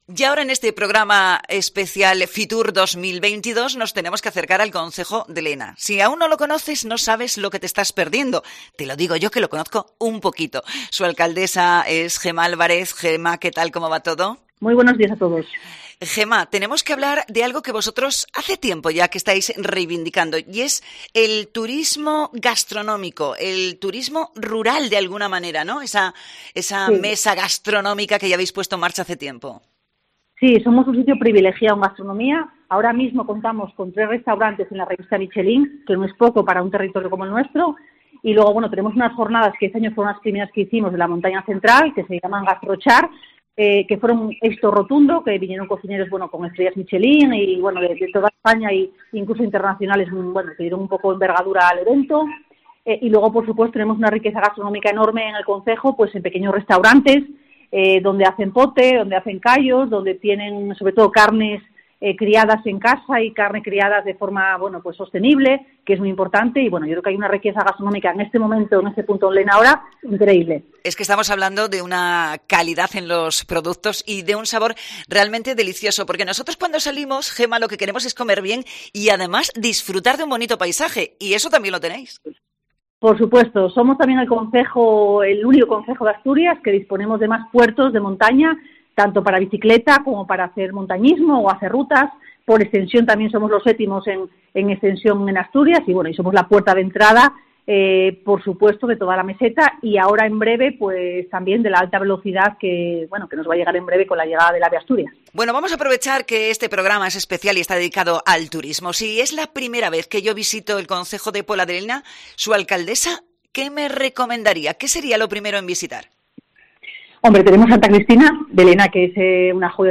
La alcaldesa lenense, Gema Álvarez, ha pasado por el especial de COPE en la Feria Internacional de Turismo desde IFEMA Madrid
Fitur 2022: Entrevista a la alcaldesa de Lena, Gema Álvarez